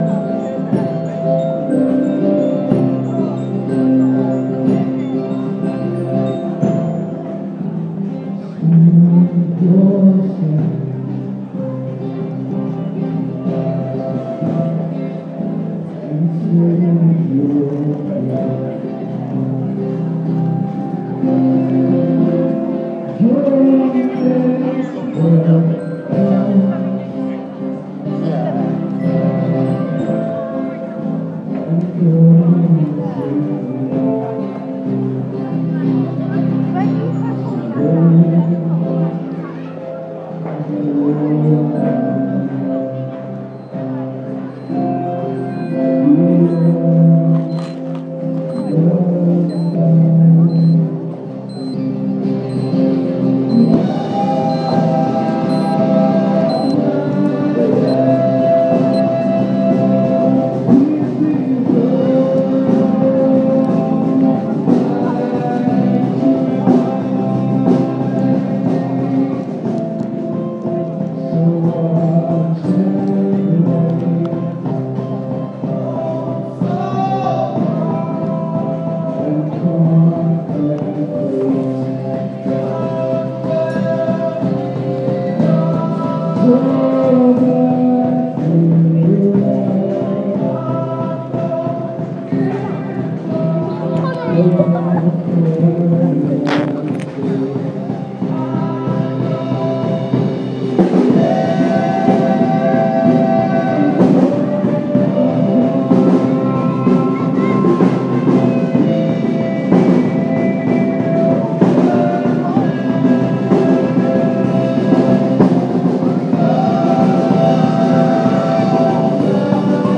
Second Song by final band outside Worcester Guildhall. Love the glockenspiel!